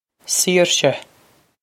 Saoirse Seer-sheh
Pronunciation for how to say
This is an approximate phonetic pronunciation of the phrase.